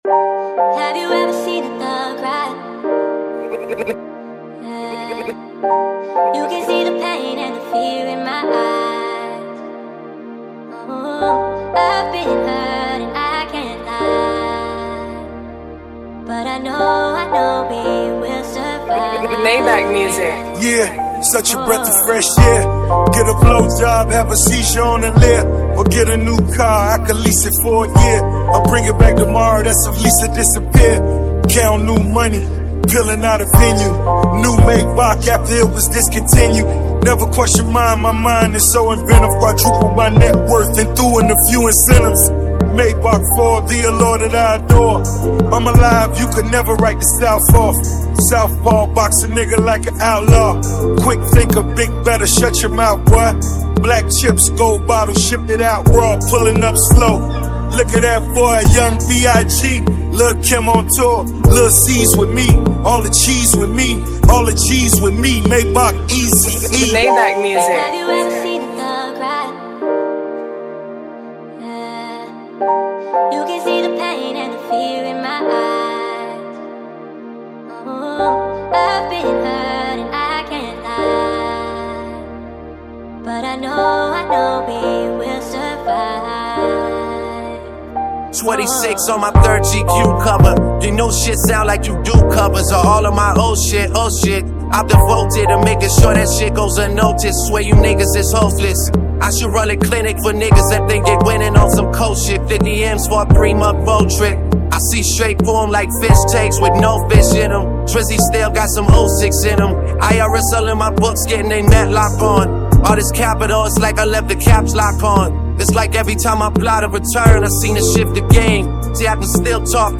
melodic hook